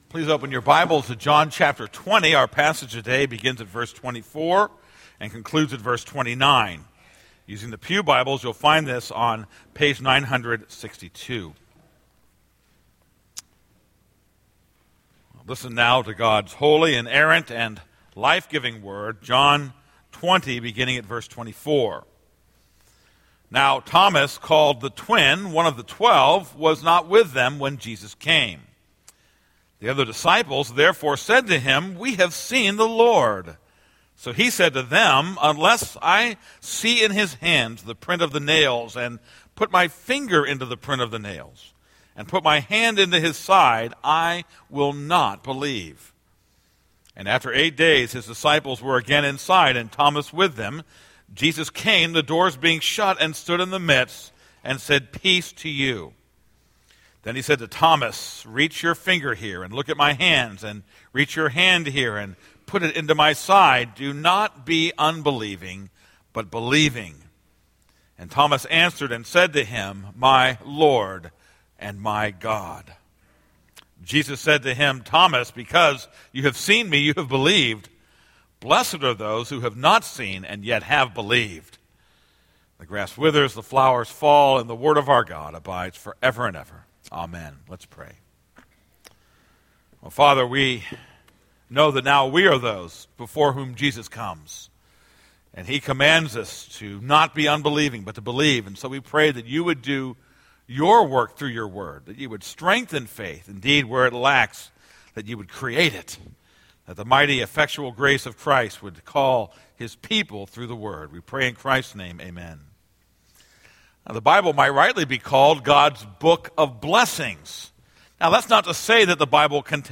This is a sermon on John 20:24-29.